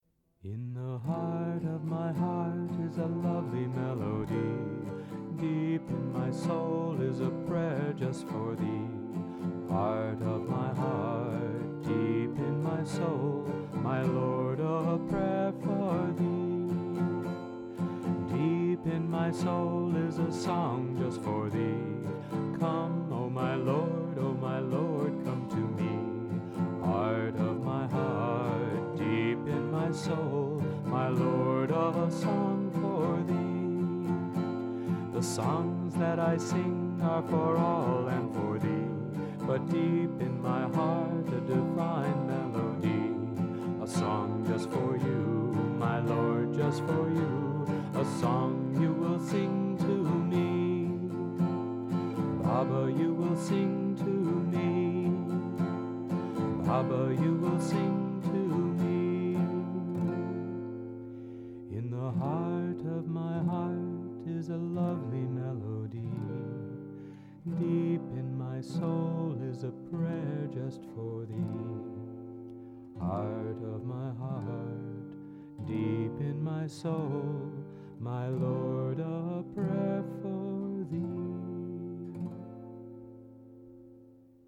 1. Devotional Songs
Major (Shankarabharanam / Bilawal)
8 Beat / Keherwa / Adi
5 Pancham / G
2 Pancham / D